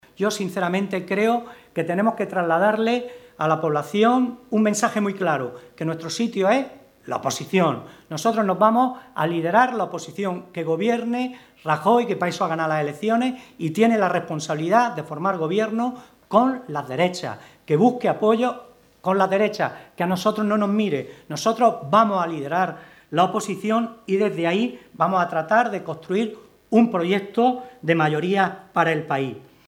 Sánchez Teruel se ha expresado de este modo en el Comité Provincial que el PSOE de Almería ha celebrado esta mañana en el municipio de Carboneras.
Comité Provincial del PSOE de Almería celebrado en Carboneras